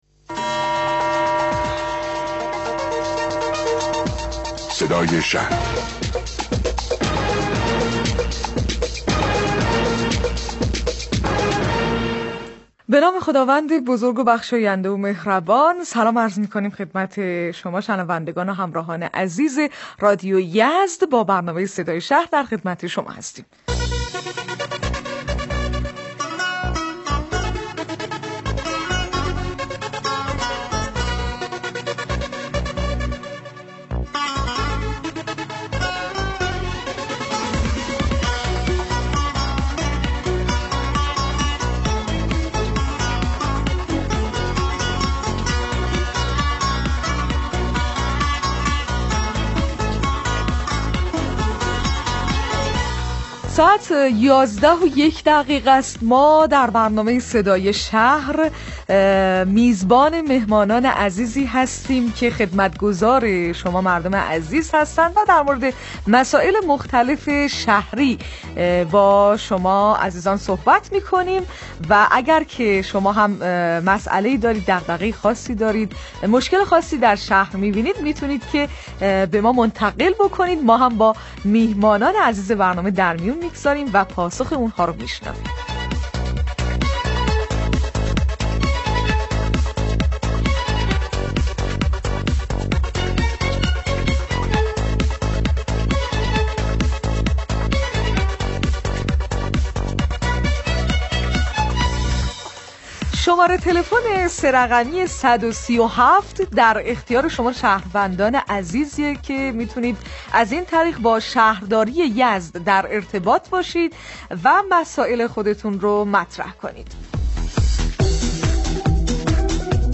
مصاحبه رادیویی برنامه صدای شهر با حضور فاطمه زارع عضو شورای اسلامی شهر یزد